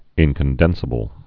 (ĭnkən-dĕnsə-bəl)